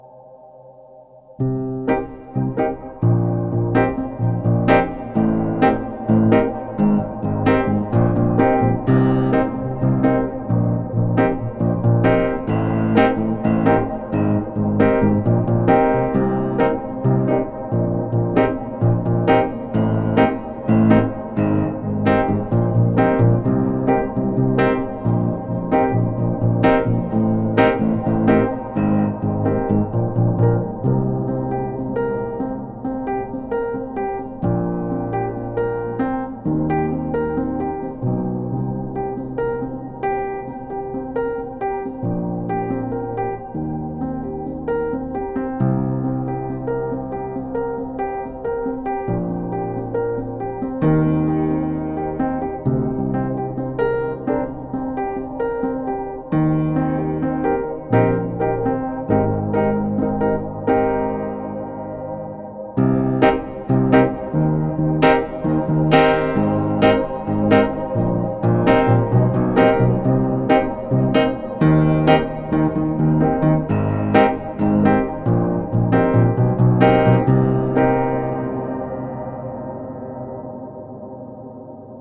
Proel DIRECT100A - klawiszowe